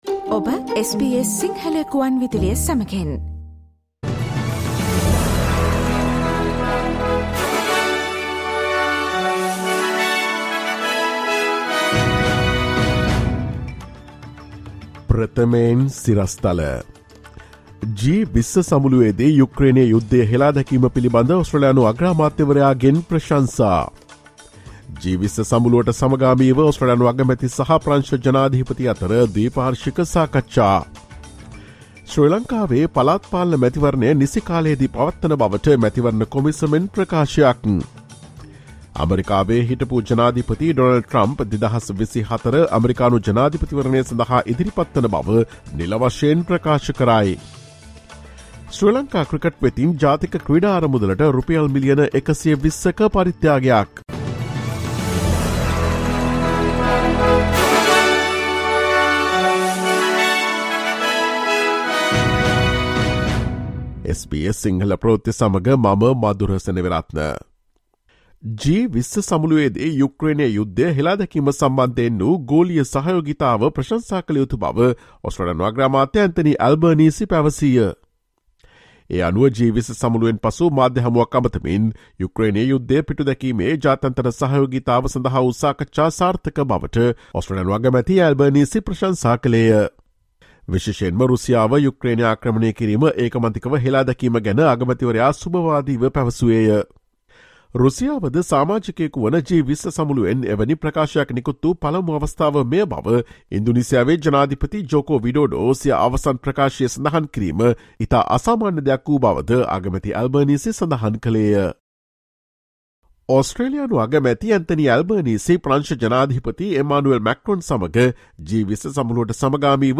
Listen to the latest news from Australia, Sri Lanka across the globe, and the latest news from the sports world on SBS Sinhala radio news – Thursday, 17 November 2022.